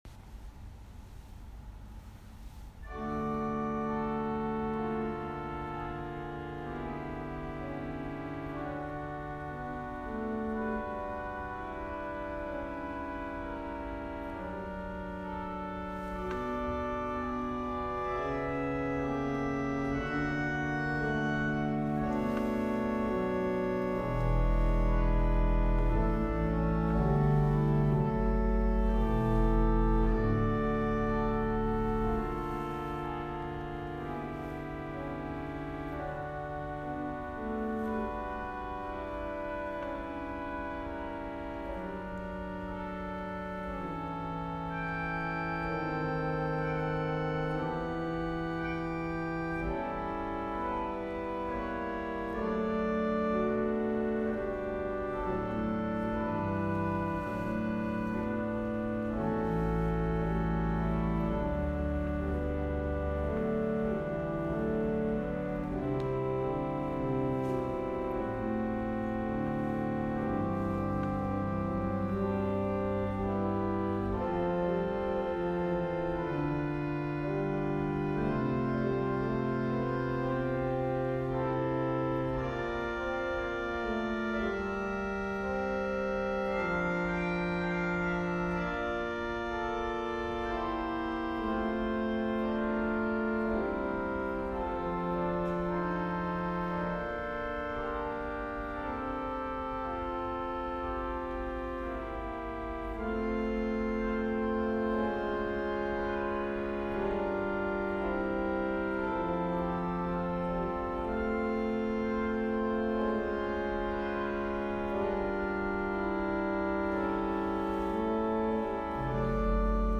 Täglich veröffentlicht die Landeskirche Anhalts als Video oder Audio ein geistliches Musikstück mit Musikerinnen und Musikern aus Anhalt sowie Informationen dazu und der jeweiligen Tageslosung mit Lehrtext.
„Christ ist erstanden“ – Anklänge an den Osterchoral
Violine